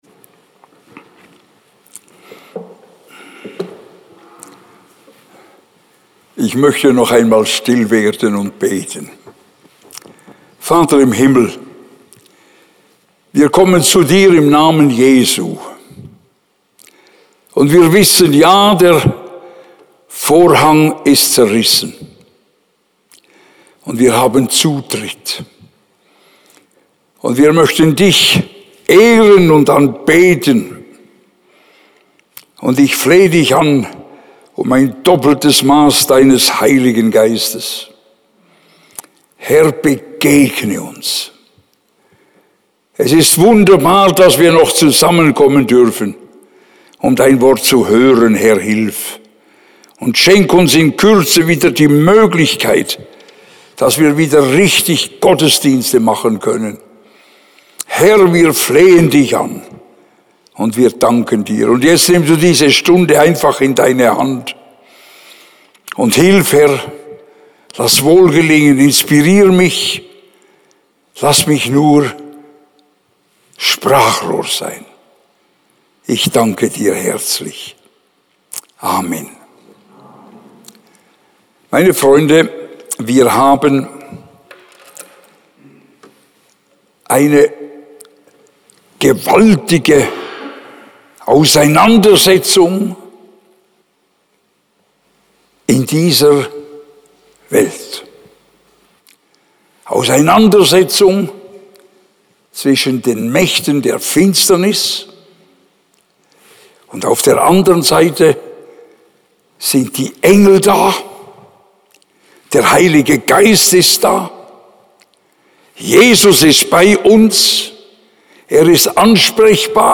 fertige-Predigt-4.mp3